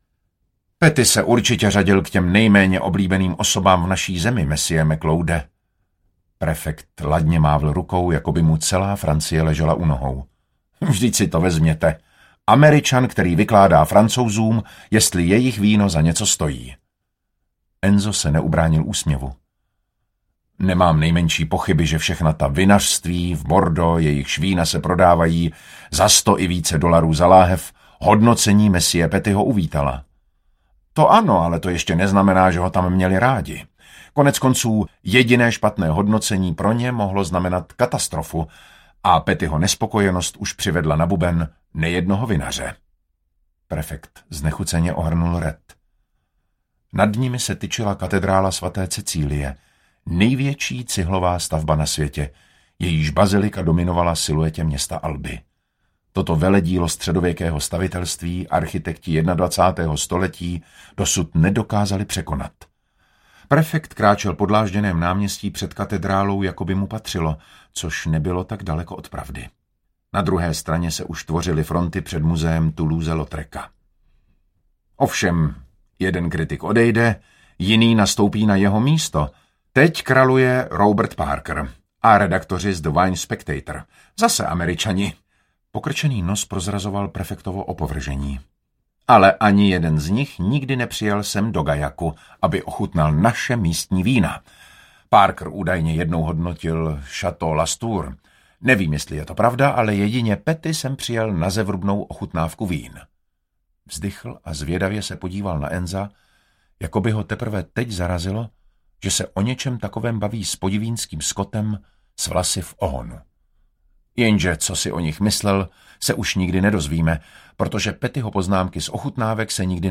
Kritik audiokniha
Ukázka z knihy
• InterpretDavid Matásek